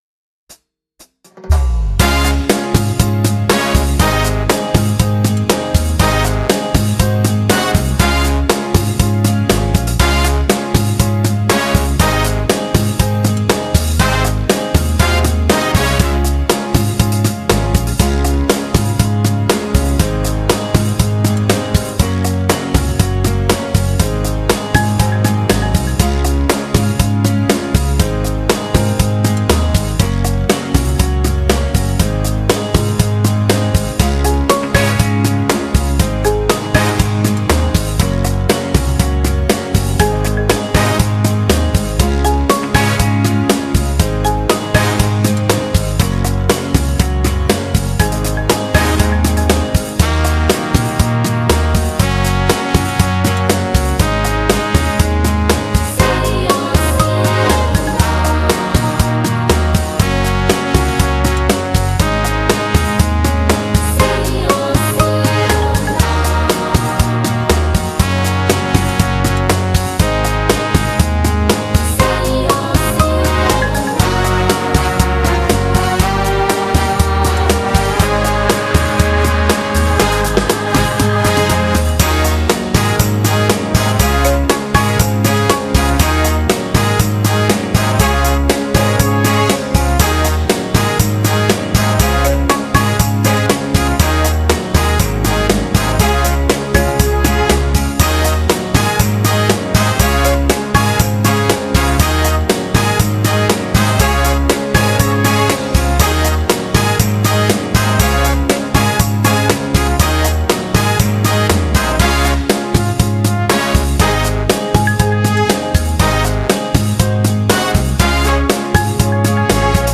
Genere: Moderato